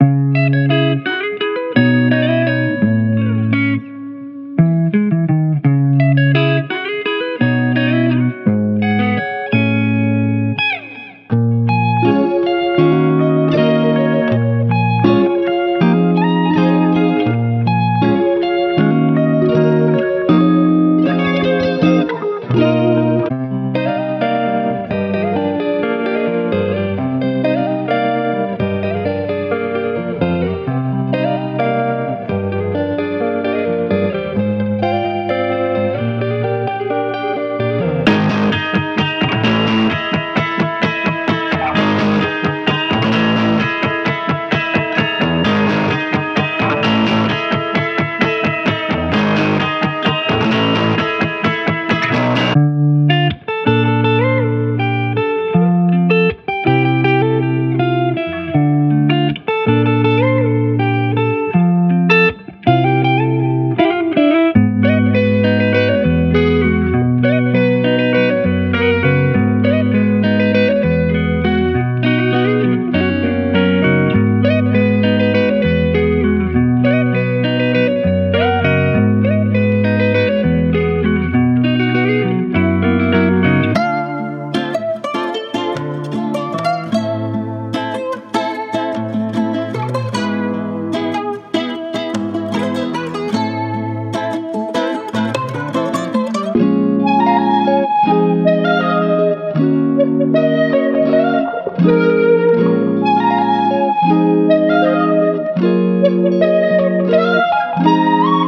Guitar Loops Demo